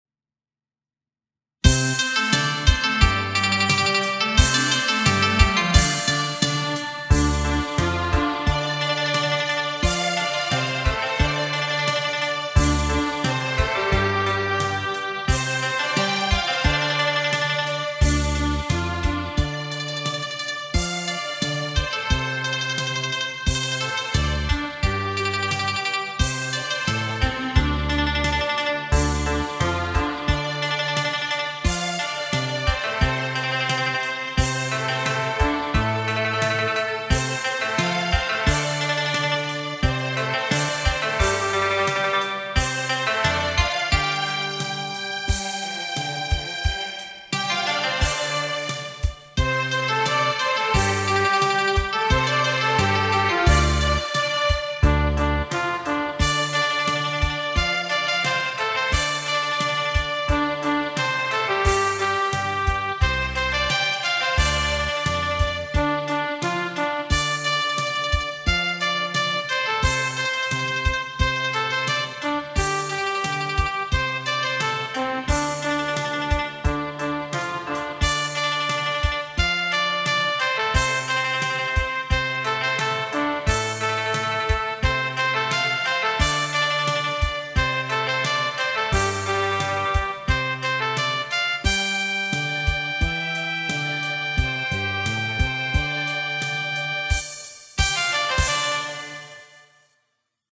还有那背后那个性感的男声伴唱